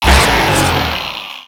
Cri de Minotaupe dans Pokémon X et Y.